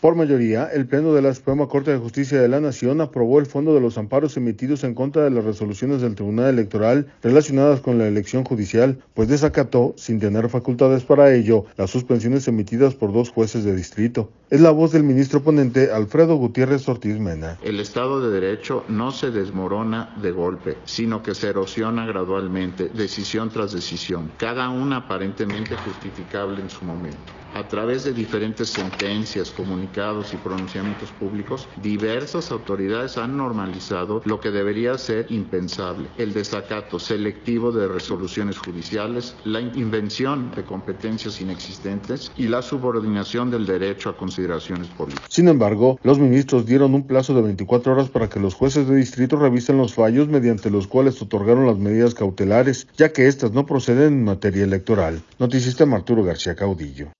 audio Por mayoría, el Pleno de la Suprema Corte de Justicia de la Nación, aprobó el fondo de los amparos emitidos en contra de las resoluciones del Tribunal Electoral relacionadas con la elección judicial, pues desacató, sin tener facultades para ello, las suspensiones emitidas por dos jueces de distrito. Es la voz del ministro ponente Alfredo Gutiérrez Ortíz Mena.